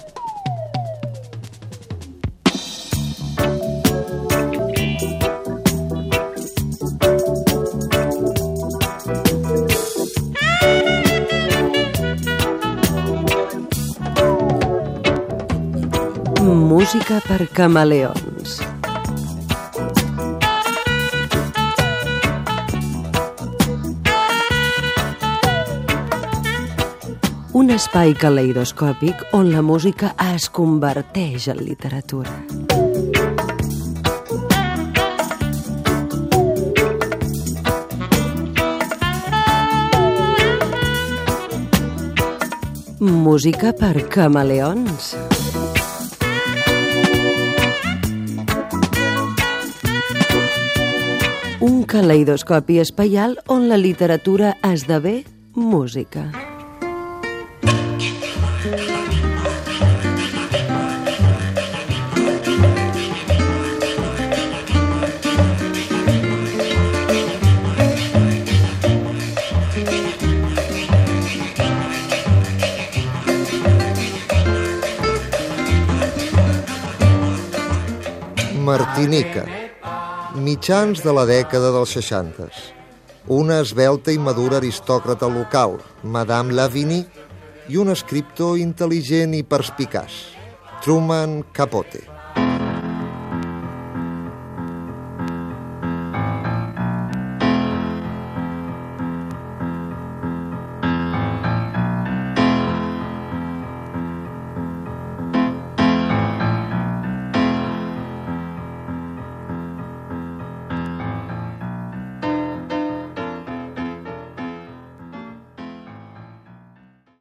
Careta d'entrada i espai dedicat a la música de la Martinica i a l'escriptor Truman Capote.
Musical